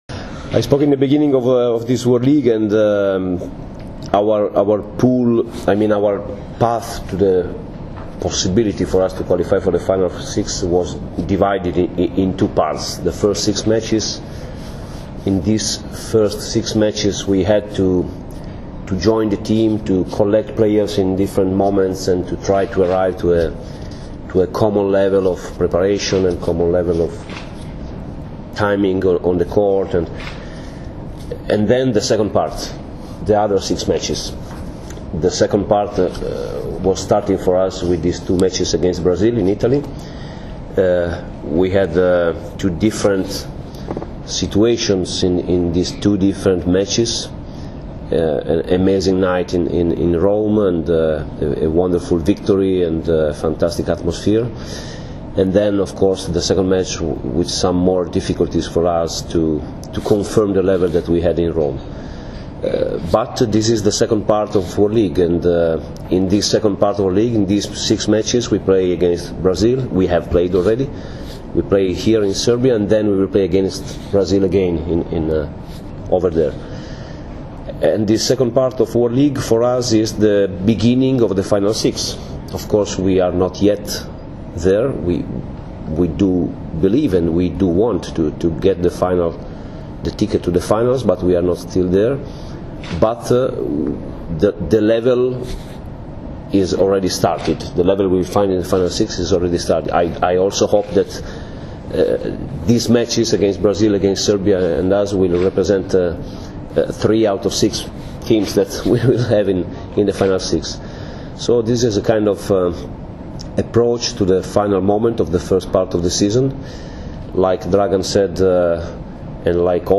U novosadskom hotelu “Sole mio” danas je održana konferencija za novinare, kojoj su prisustvovali Dragan Stanković, Nikola Grbić, Dragan Travica i Mauro Beruto, kapiteni i treneri Srbije i Italije.
IZJAVA MAURA BERUTA